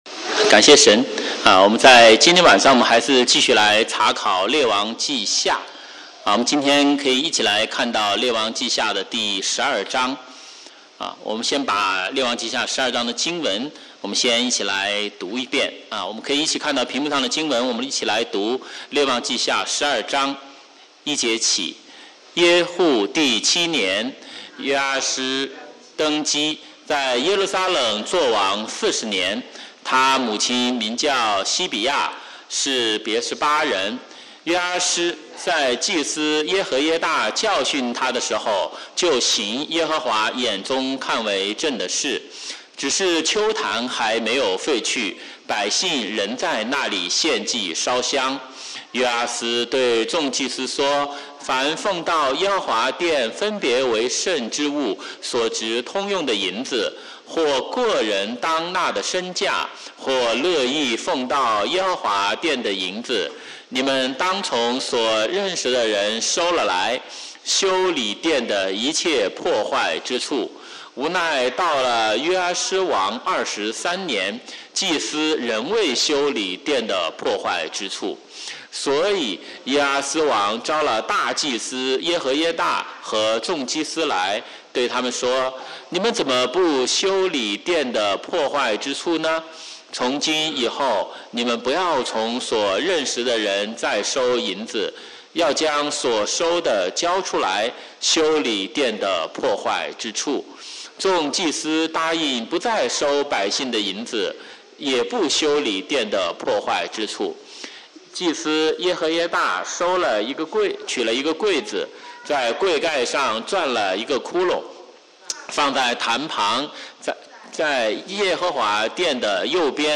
華語主日崇拜講道錄音